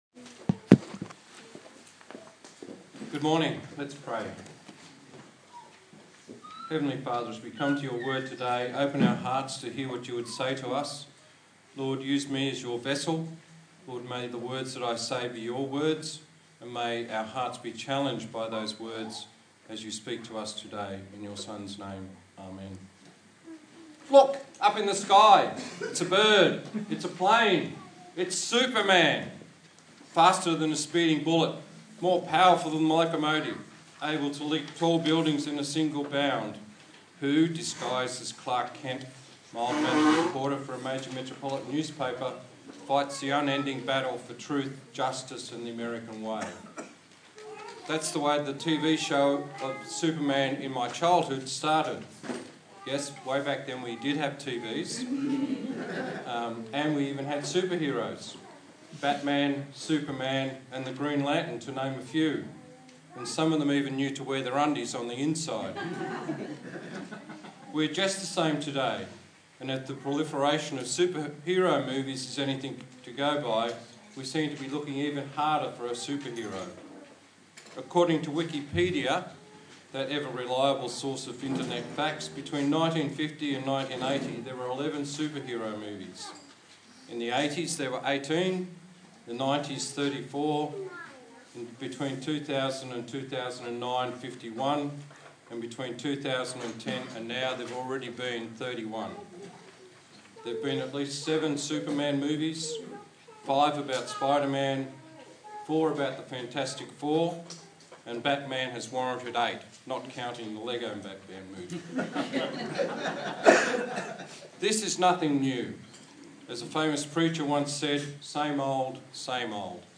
1 Samuel Passage: 1 Samuel 11 Service Type: Sunday Morning